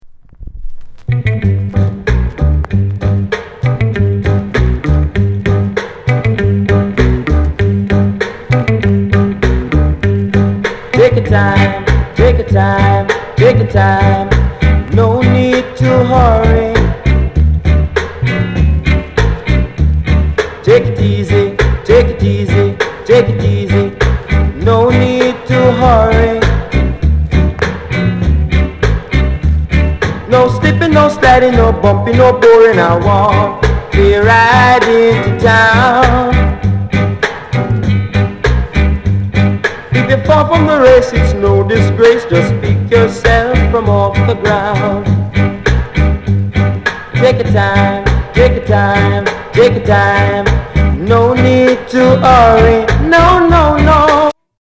SOUND CONDITION A SIDE VG
ROCKSTEADY